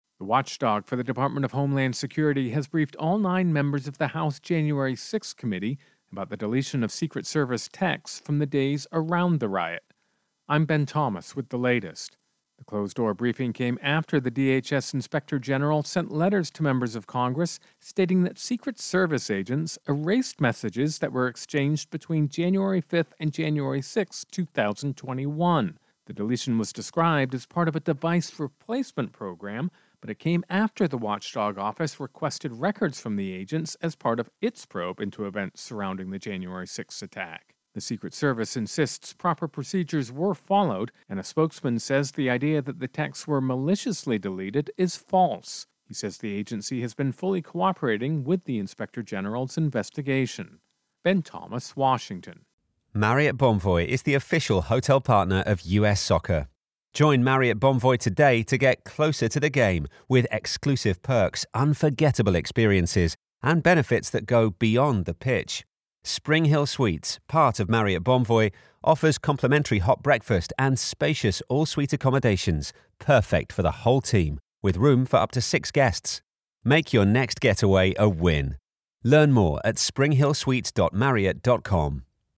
reports on Capitol Riot Investigation-Secret Service.